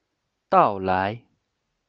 到来/dàolái/Próximamente, venir